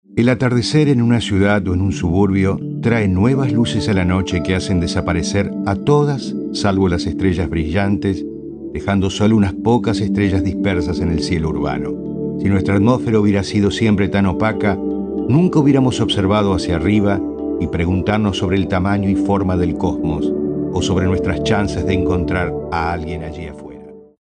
Male Professional Voice Over Talent | VoicesNow Voiceover Actors